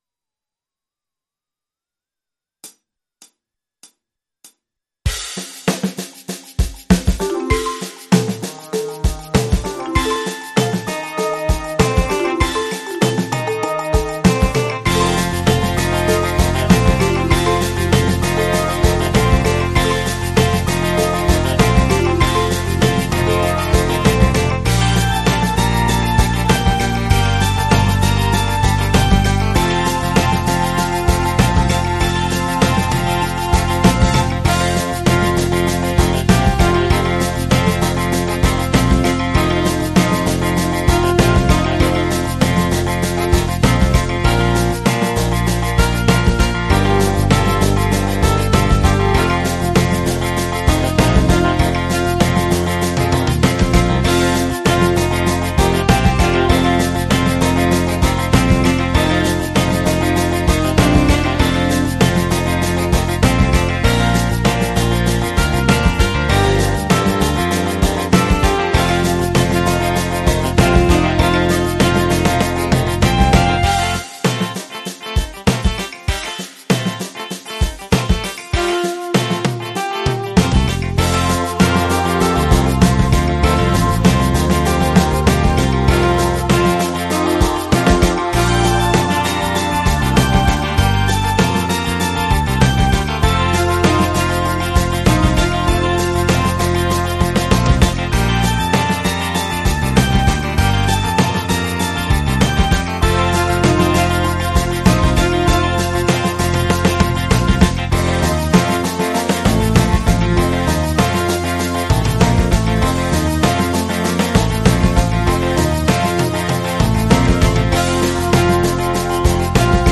la version instrumentale multipistes